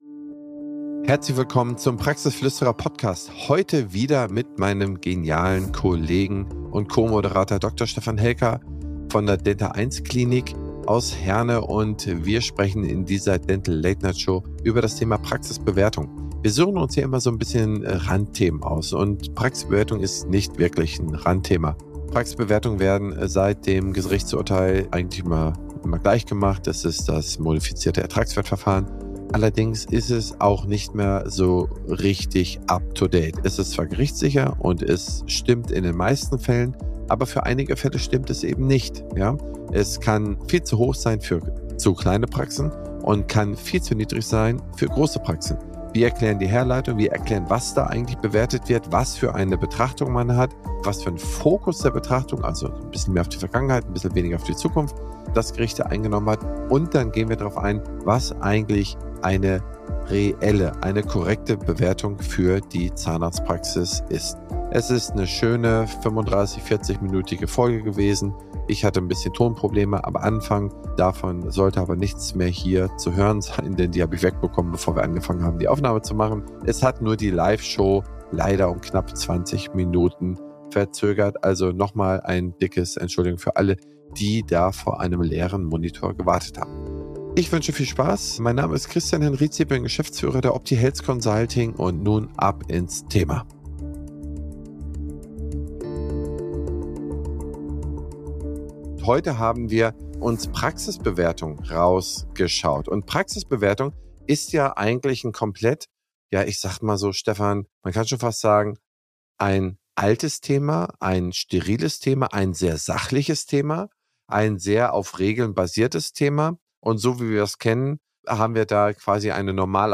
In dieser Episode, der Aufzeichnung der Dental Late Night Show September, dreht sich alles um das vielschichtige Thema Praxisbewertung.